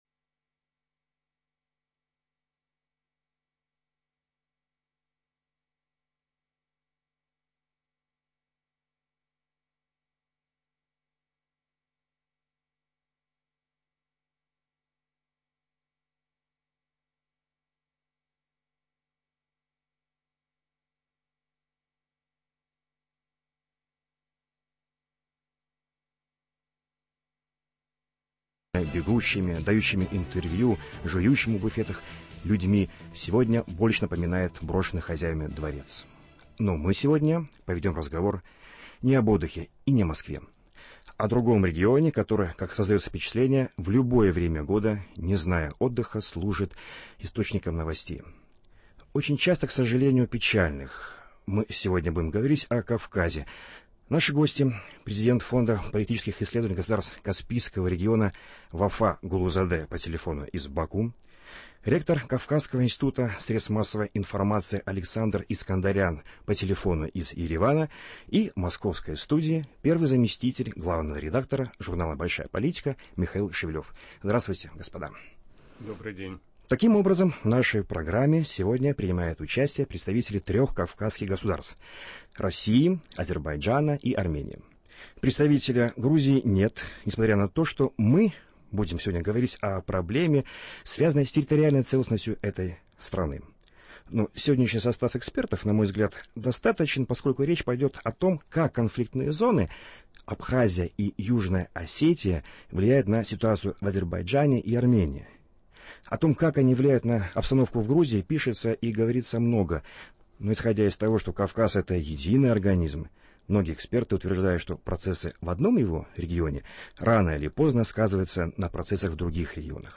по телефону из Баку
по телефону из Еревана, и в московской студии